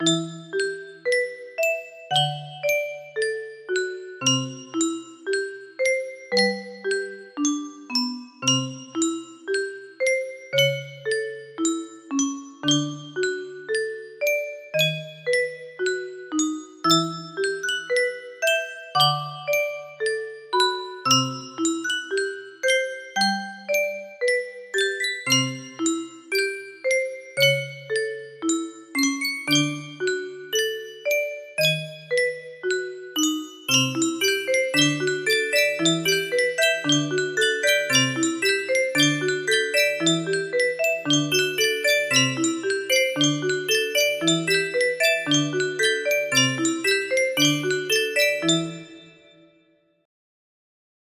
A Maniac Is Born... music box melody
Full range 60